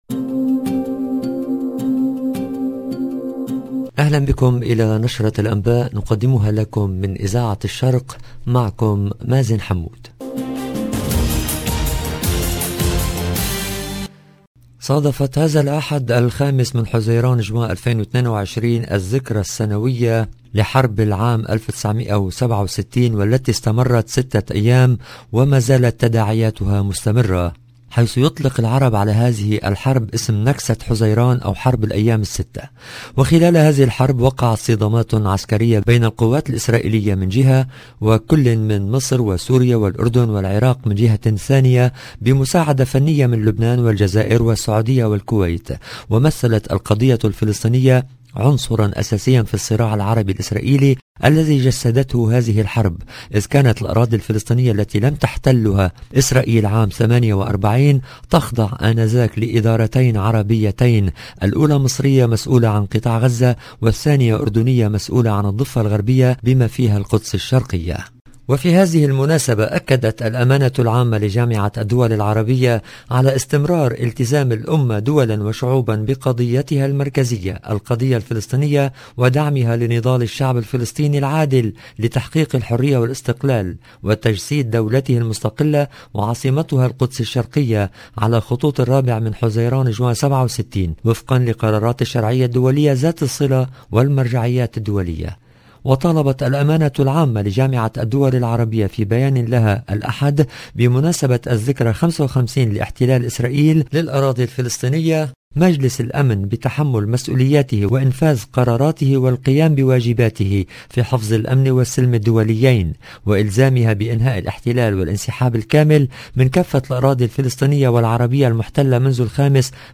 LE JOURNAL DU SOIR EN LANGUE ARABE DU 5/6/2022